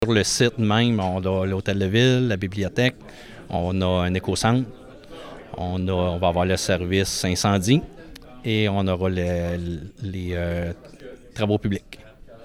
L’annonce a été faite en conférence de presse, jeudi après-midi.
Les deux bâtiments seront adjacents aux services municipaux déjà existants, comme l’a précisé le maire de Saint-Léonard-d’Aston, Laurent Marcotte.